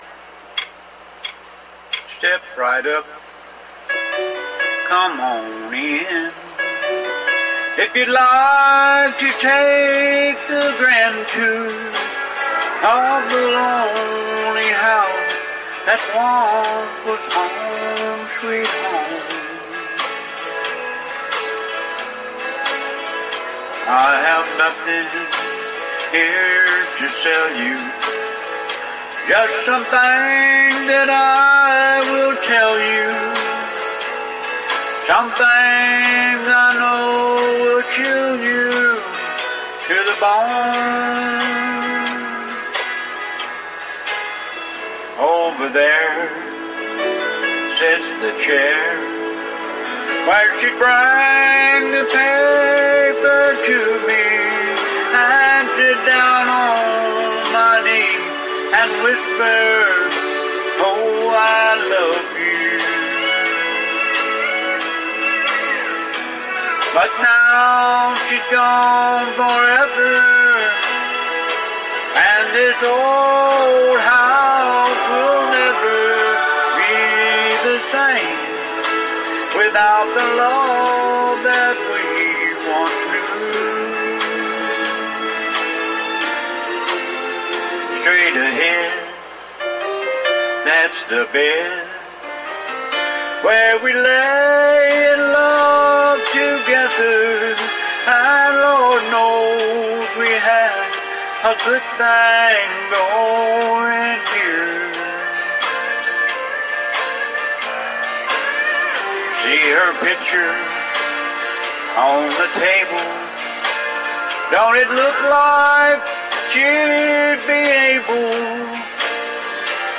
WGXC Live: The Karaoke Call-In Show (Audio) Jun 28, 2019 shows WGXC Live Standing Wave Radio Live broadcasts The Karaoke Call-In Show : Jun 28, 2019: 8pm - 9pm You were born like this, you had no choice, you we...
Tune in and sing along to karaoke versions of your favorite jams or call in and let your voice ring over the airwaves.